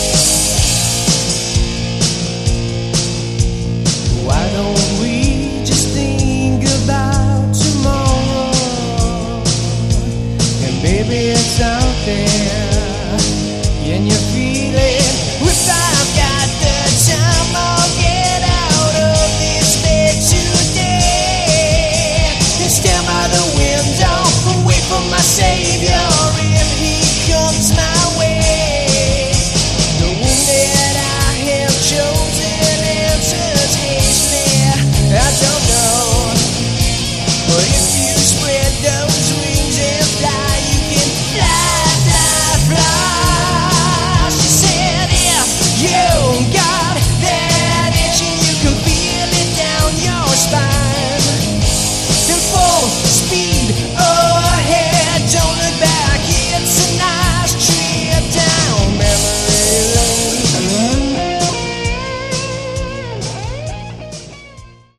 Category: Hard Rock
Good HardRock/Glam band.